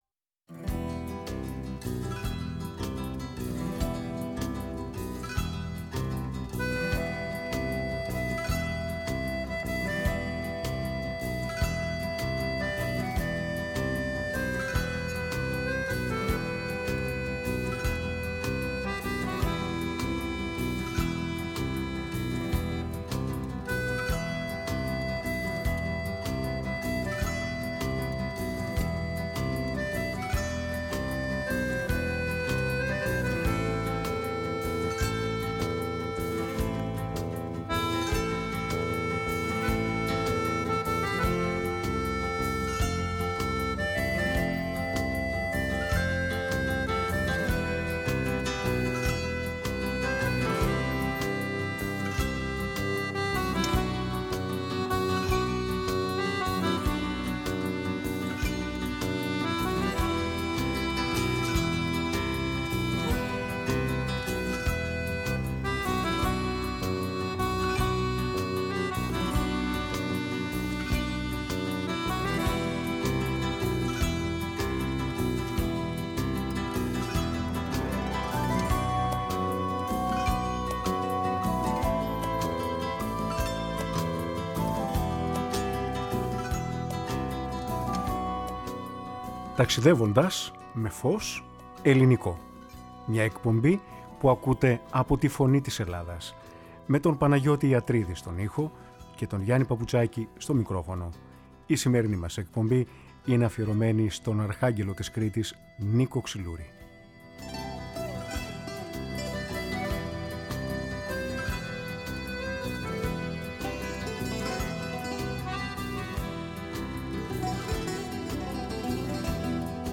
τραγούδια
Μουσική